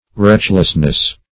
Search Result for " retchlessness" : The Collaborative International Dictionary of English v.0.48: Retchless \Retch"less\, a. Careless; reckless.